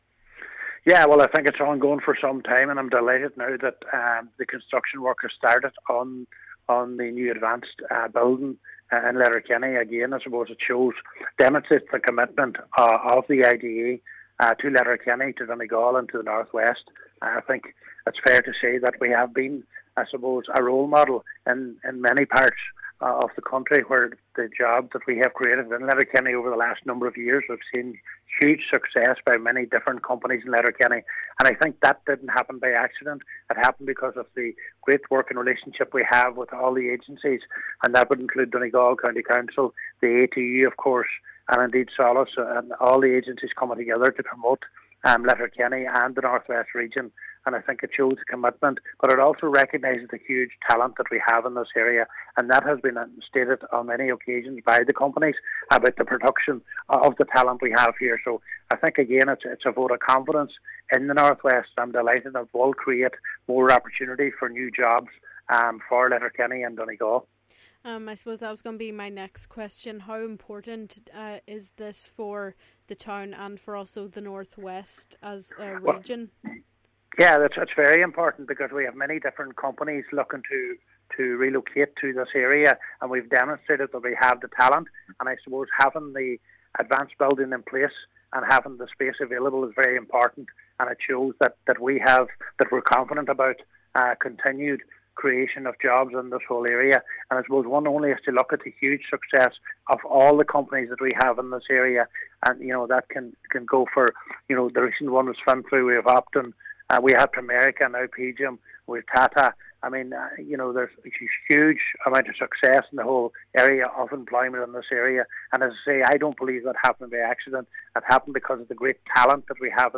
He says the development shows a commitment to making Letterkenny and the North West an attractive place to live, work and do business: